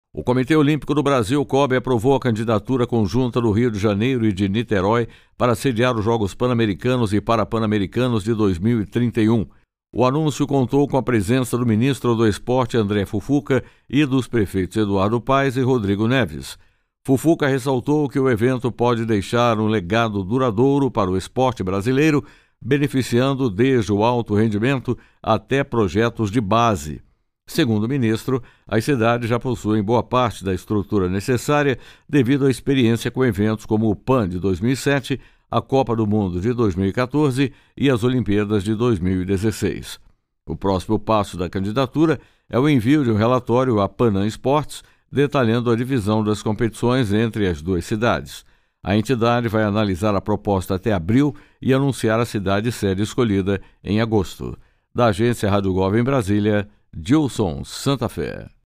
É Notícia